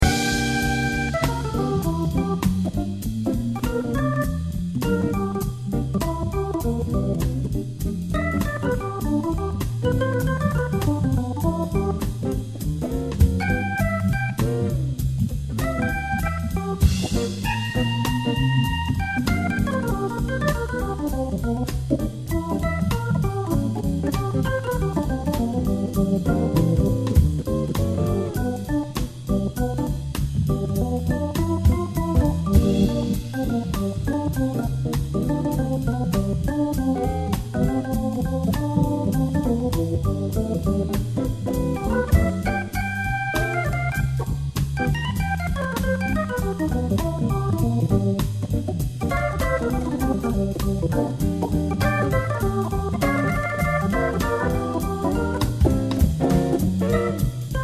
Et un B3 pour le 2 ème.